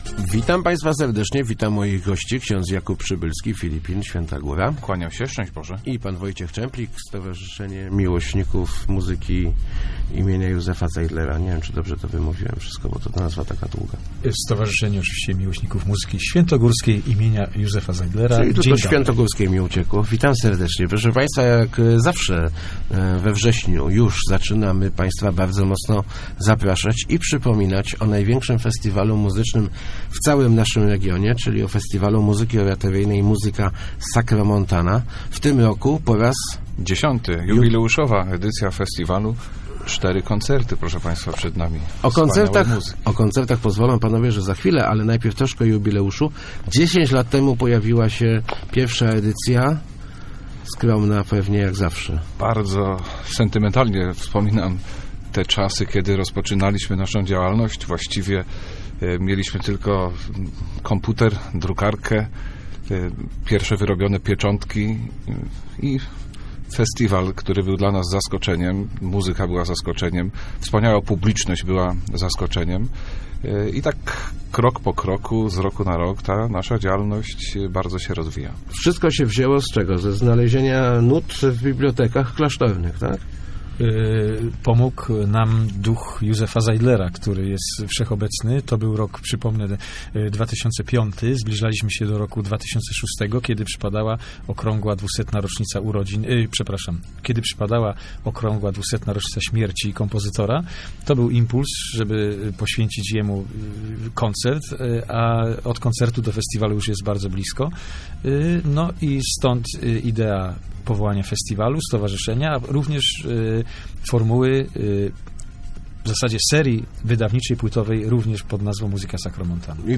Start arrow Rozmowy Elki arrow Dziesiąta Sacromontana